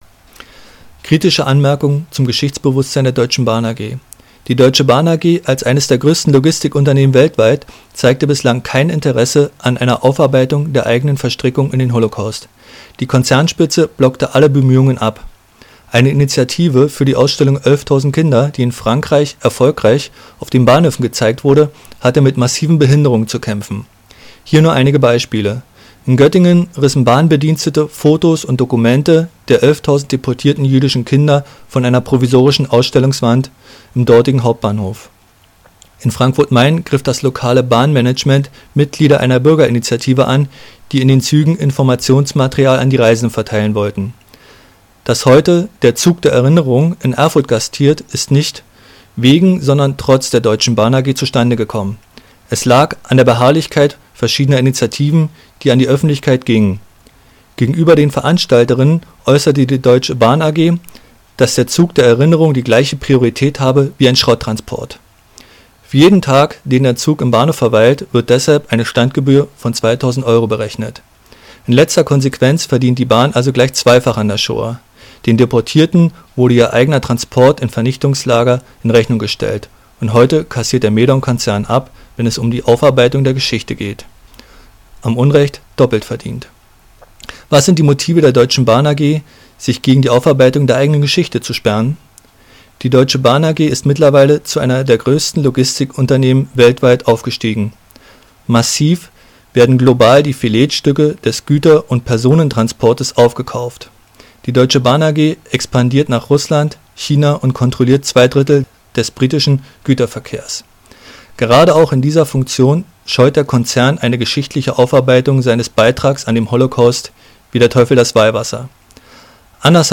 Spontankundgebung in Erfurt zum "Zug der Erinnerung"
Redebeitrag [mp3]